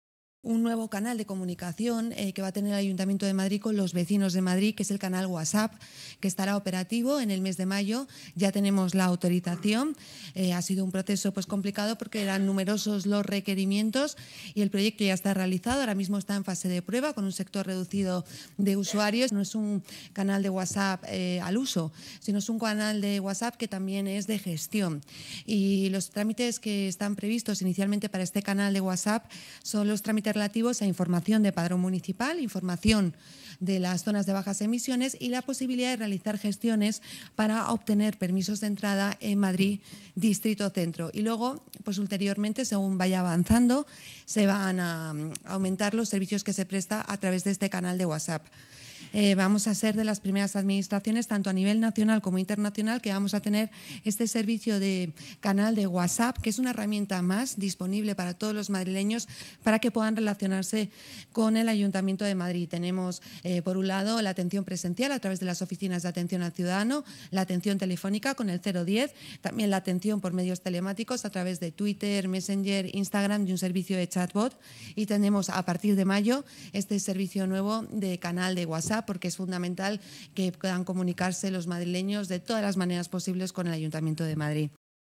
Nueva ventana:Declaraciones de la portavoz municipal, Inmaculada Sanz